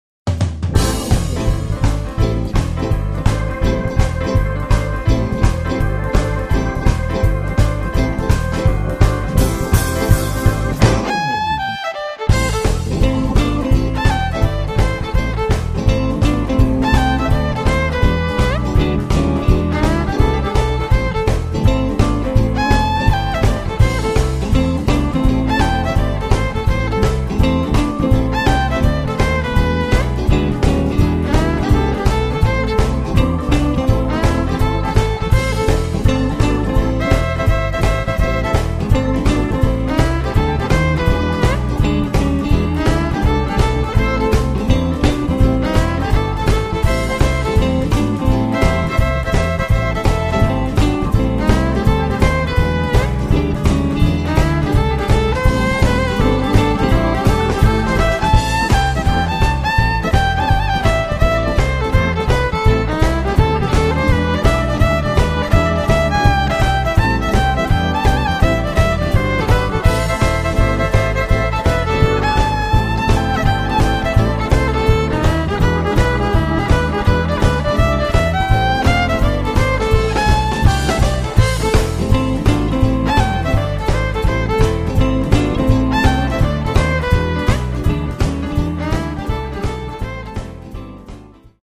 The repertoire covers a wide range of blues styles
violin
relentless drive
hard-driving